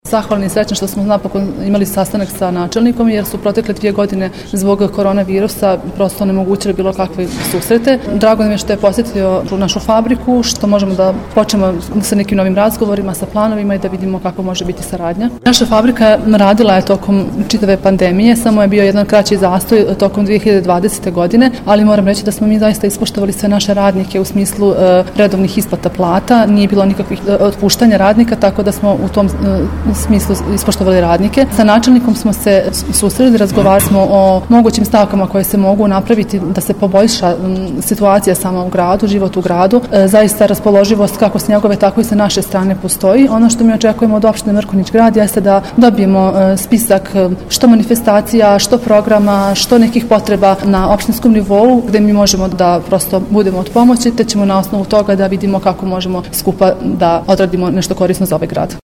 Istakao je da postoji dobro raspoloženje i od strane načelnika, i od rukovodstva fabrike, da zajedno daju svoj doprinos kako društveni život u Mrkonjić Gradu ne oskudijeva, u smislu održavanja manifestacija, programskih sadržaja iz raznih životnih sfera, itd. U nastavku poslušajte izjavu…